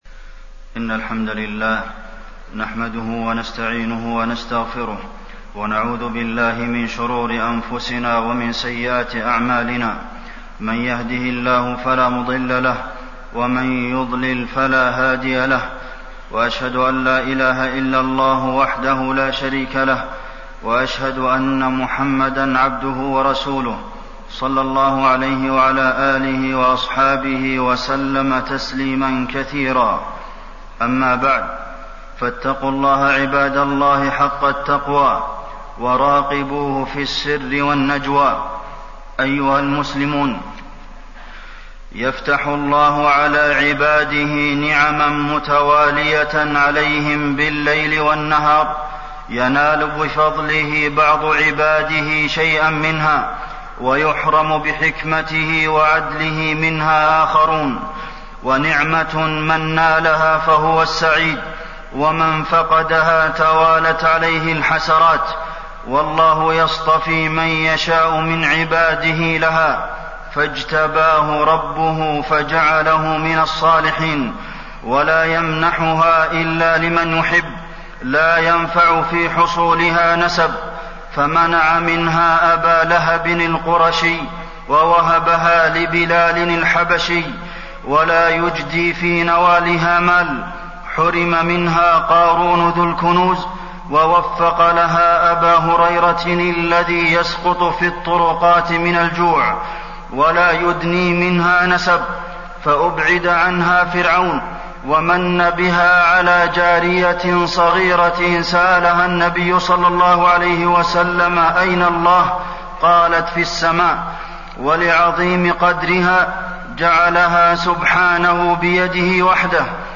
تاريخ النشر ١٢ ربيع الأول ١٤٣١ هـ المكان: المسجد النبوي الشيخ: فضيلة الشيخ د. عبدالمحسن بن محمد القاسم فضيلة الشيخ د. عبدالمحسن بن محمد القاسم نعمة الدين